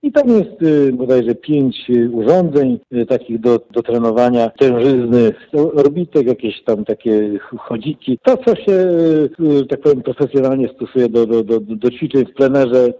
To plenerowa siłownia przy plaży wiejskiej. Mówi Marek Chojnowski, starosta ełcki: